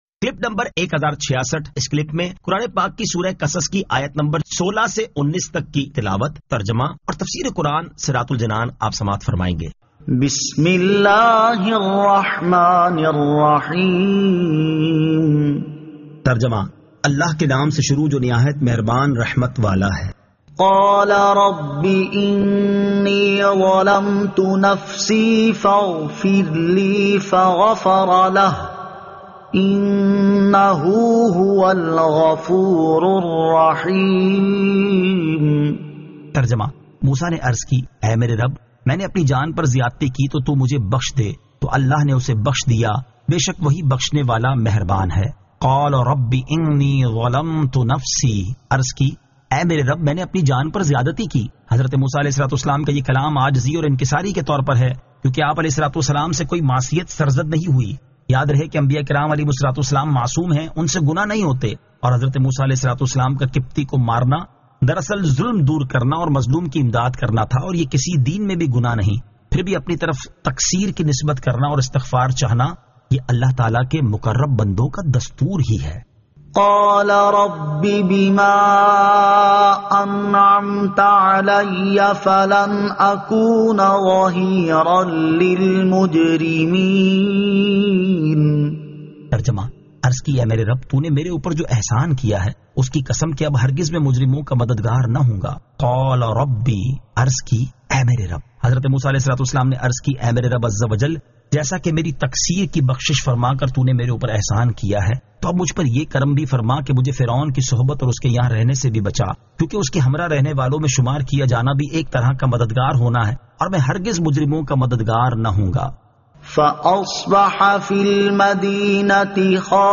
Surah Al-Qasas 16 To 19 Tilawat , Tarjama , Tafseer